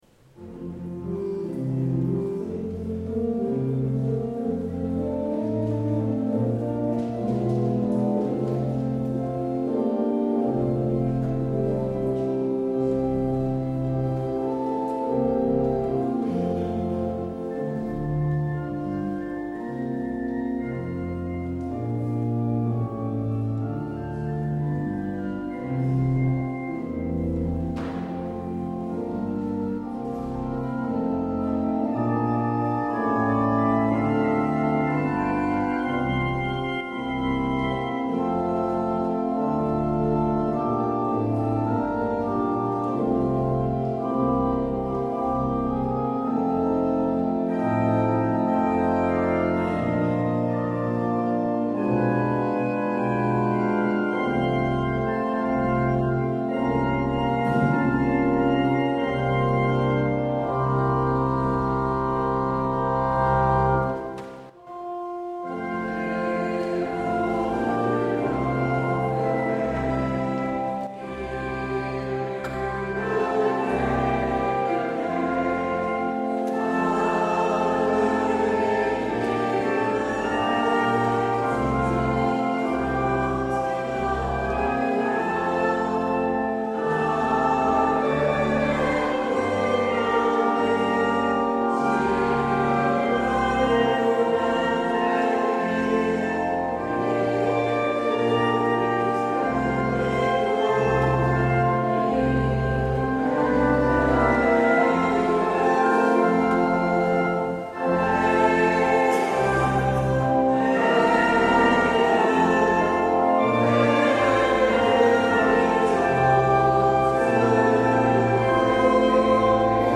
 Luister deze kerkdienst hier terug